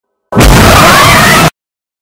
Звуки скримера, неожиданности
От этого звука просто мурашки по коже